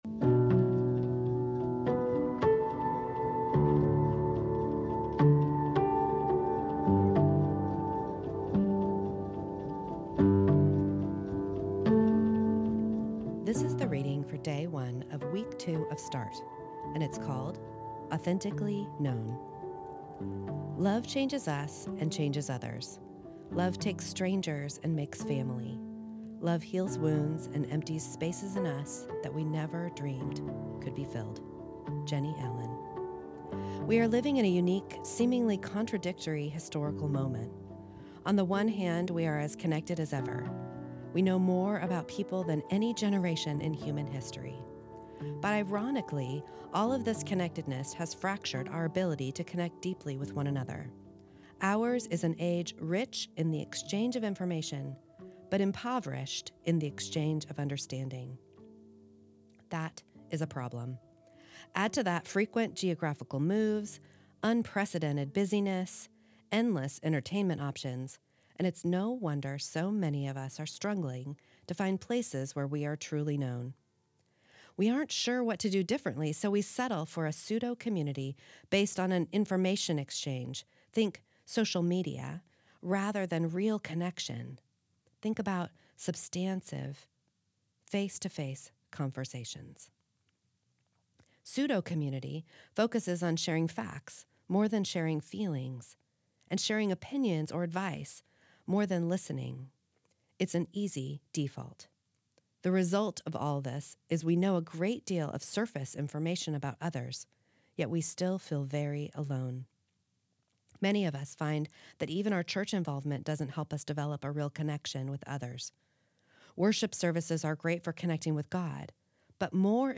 This is the audio recording of the first reading of week two of Start, entitled Authentically Known.